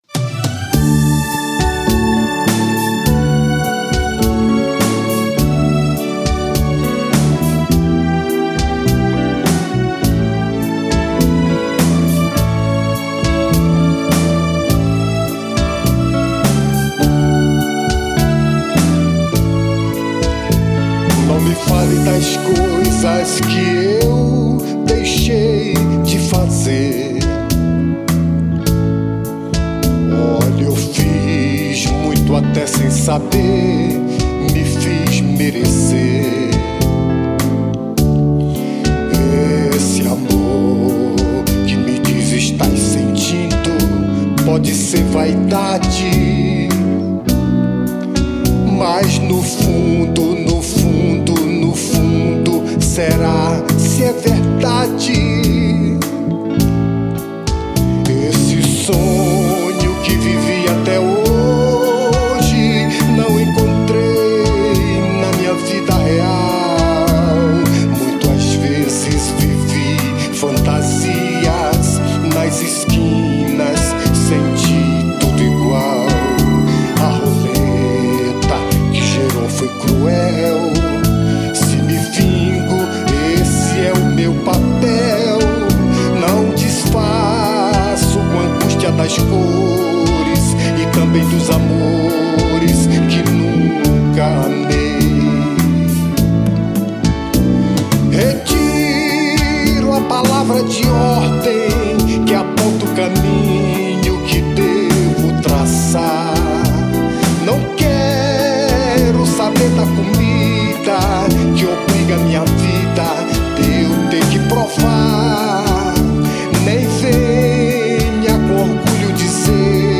EstiloRomântico